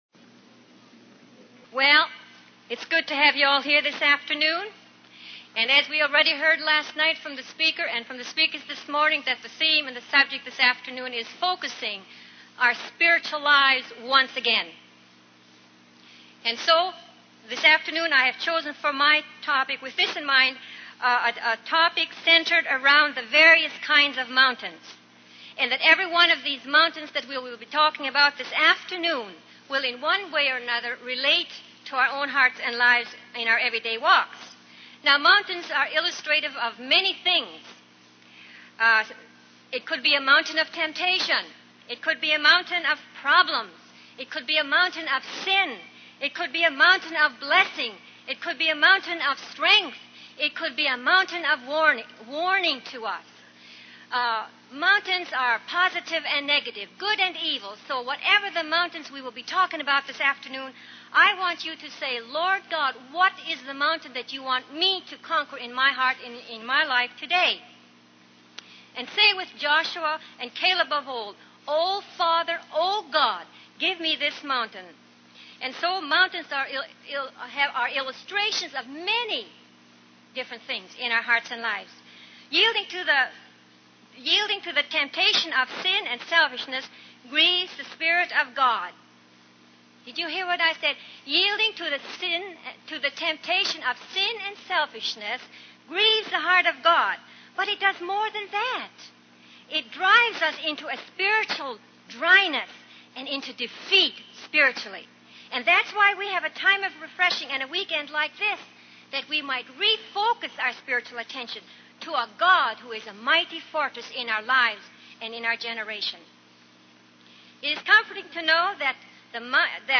In this sermon, the speaker reflects on the story of Caleb and Joshua from the Bible.